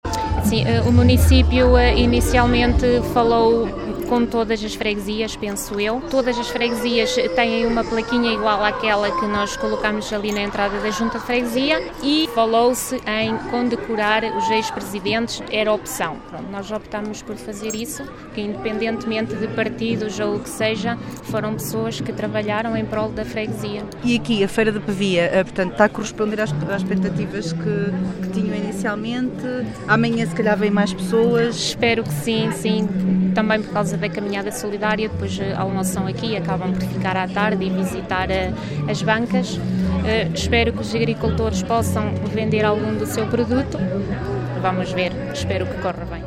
No âmbito da inauguração da feira, este sábado foram homenageados os sete presidentes de junta que exerceram o cargo, depois do 25 de abril, para além de ser também realizada a inauguração de uma placa comemorativa, como destaca a presidente de Junta de Freguesia de Arcas, Ana Martins: